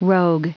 Prononciation du mot rogue en anglais (fichier audio)
Prononciation du mot : rogue